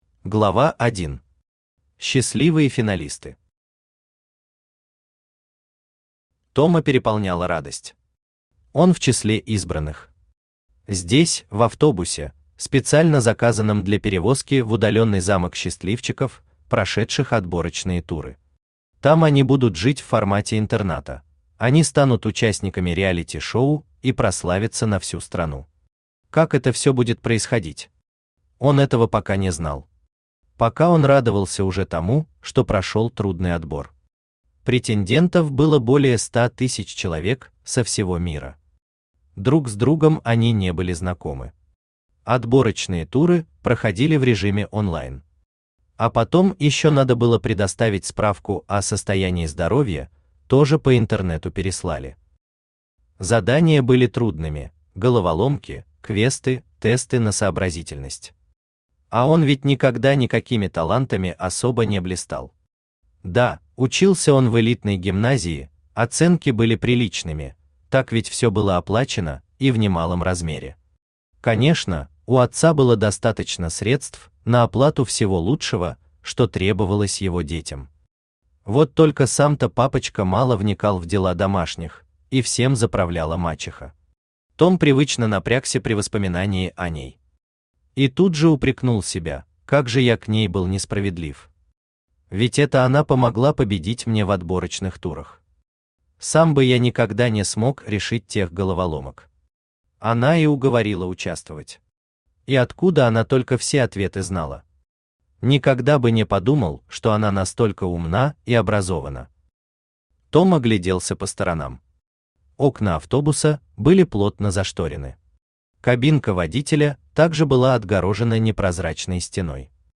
Аудиокнига Заключительный тур. Детектив в декорациях пандемии | Библиотека аудиокниг
Детектив в декорациях пандемии Автор Агоштон Орос Читает аудиокнигу Авточтец ЛитРес.